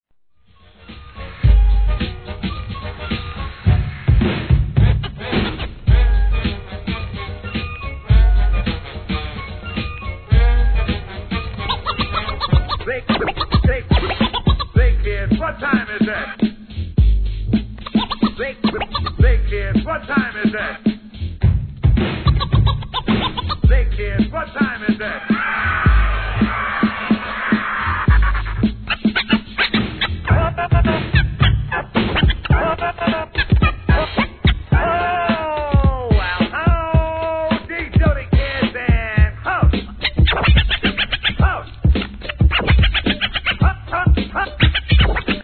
HIP HOP/R&B
見るからにハーコー?なBREAK BEATS物!! タイトル通りのT.V.サンプリングネタ満載の隅に置けない逸品!!